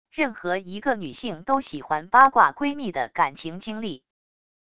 任何一个女性都喜欢八卦闺蜜的感情经历wav音频生成系统WAV Audio Player